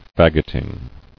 [fag·ot·ing]